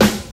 23 SNARE 4.wav